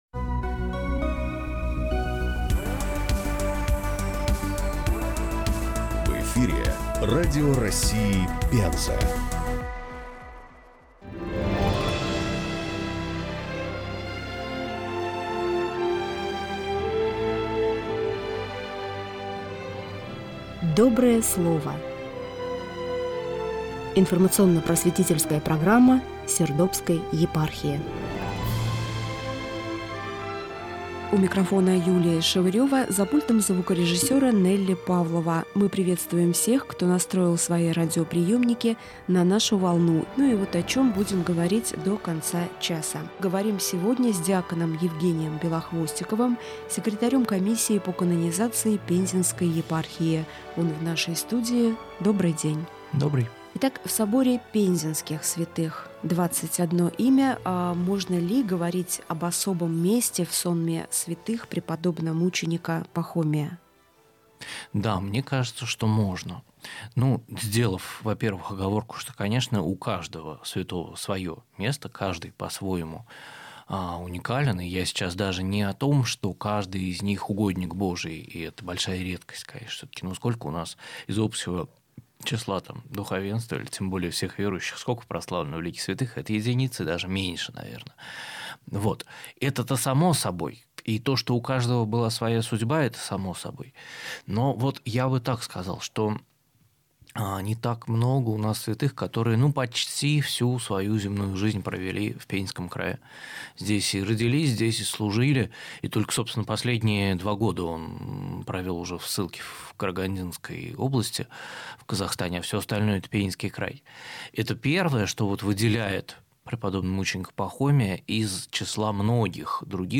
беседует